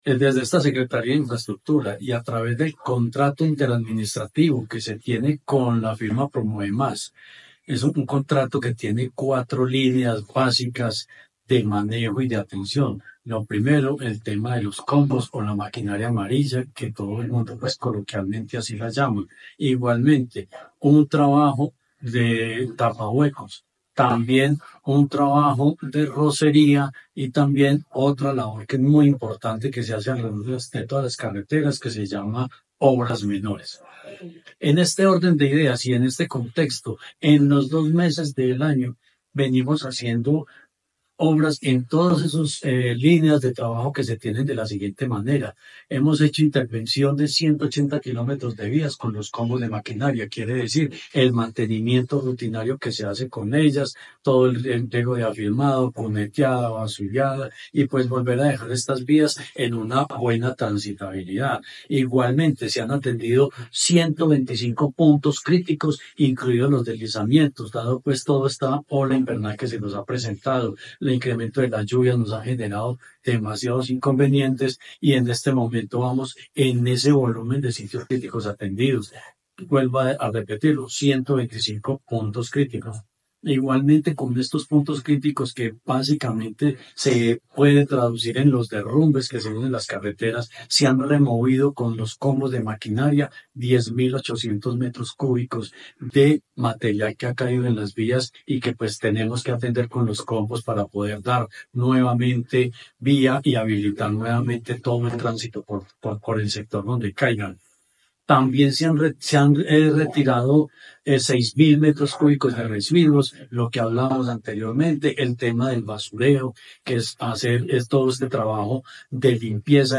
Secretario de Infraestructura, Jorge Ricardo Gutiérrez Cardona.
Ful-Jorge-Ricardo-Gutierrez-Cardona.mp3